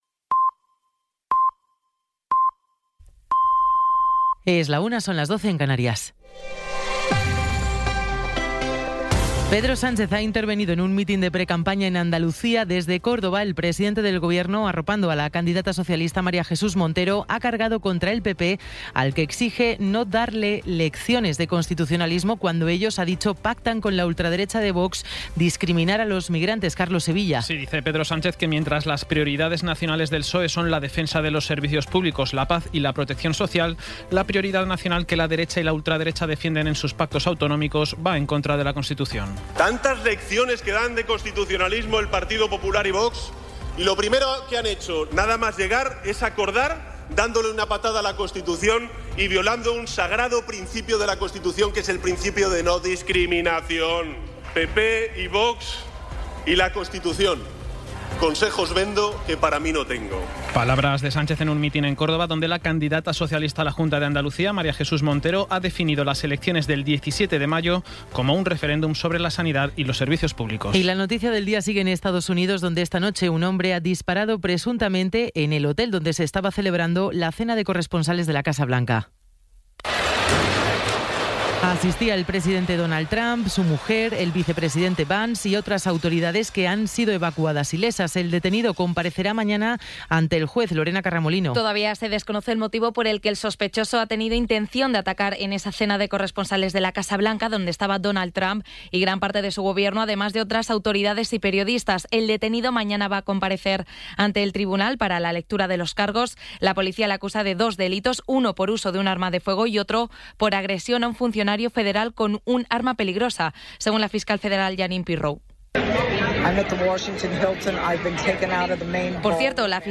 Resumen informativo con las noticias más destacadas del 26 de abril de 2026 a la una de la tarde.